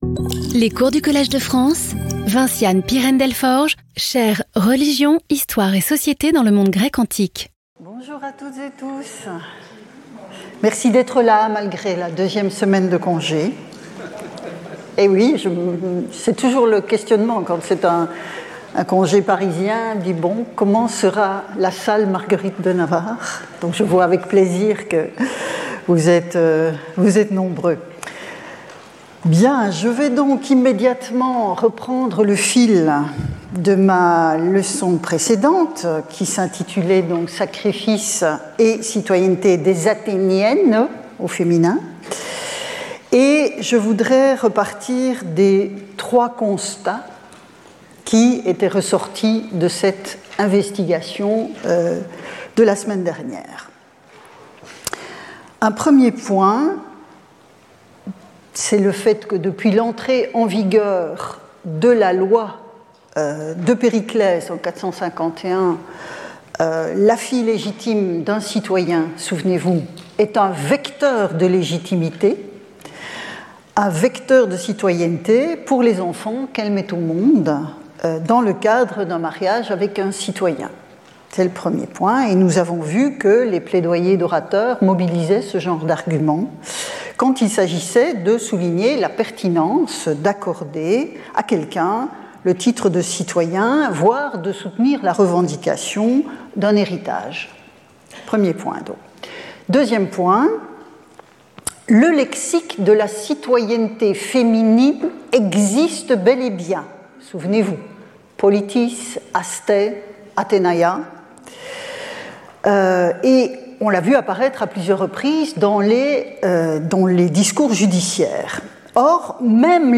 Vinciane Pirenne-Delforge Professeure du Collège de France